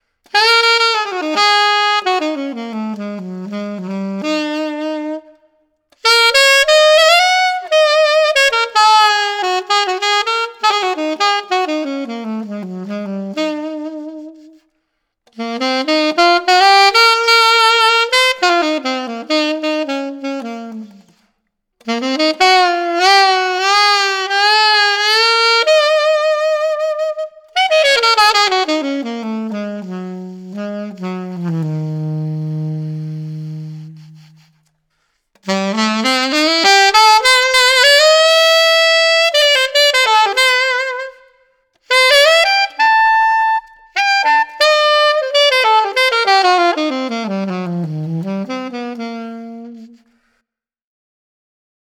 The third sound clip is of some bluesy lines and a little bit of altissimo with some reverb added so you can hear how the mouthpiece sounds in that range of the saxophone with reverb.
Kessler Custom NY7 Alto Saxophone Mouthpiece by Theo Wanne – Bluesy Lines with Reverb Added – BSS (Boston Sax Shop) #3 Silver Label Reed
KesslerCAlto7-Bluesy-Reverb.mp3